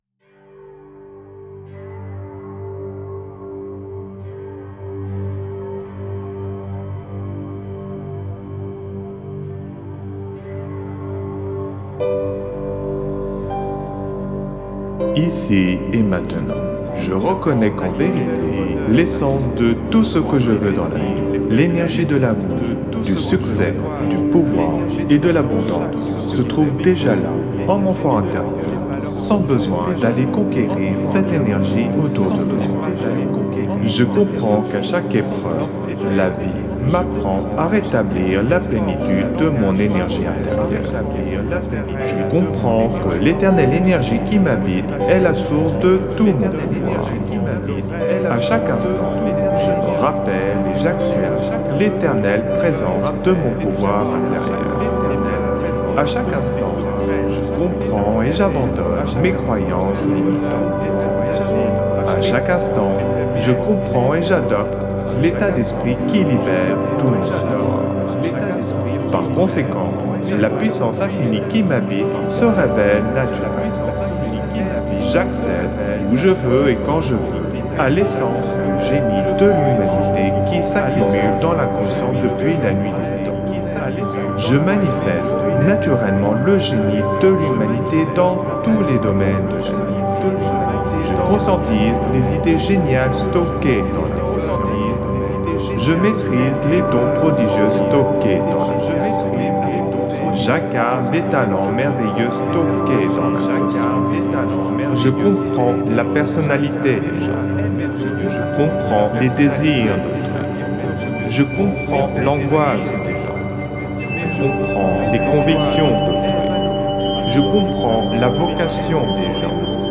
(Version ÉCHO-GUIDÉE)
Alliage ingénieux de sons et fréquences curatives, très bénéfiques pour le cerveau.
Puissant effet 3D subliminal écho-guidé.